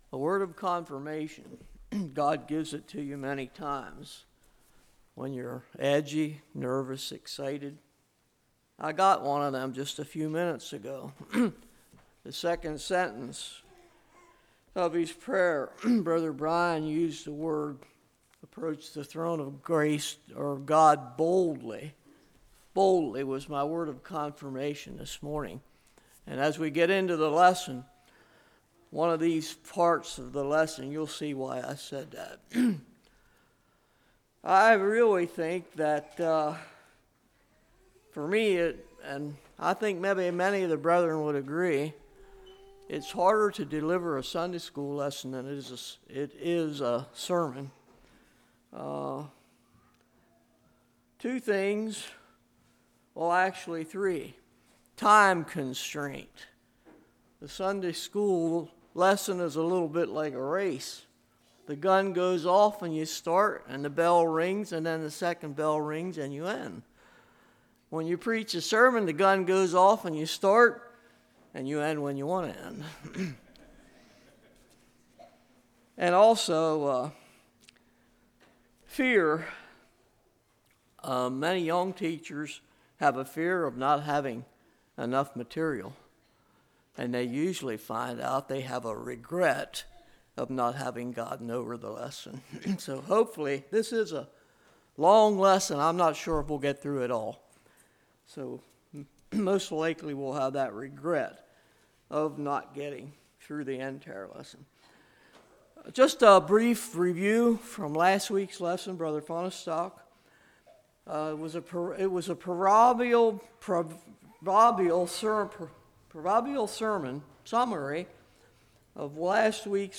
Series: Fall Revival 2012
Service Type: Sunday School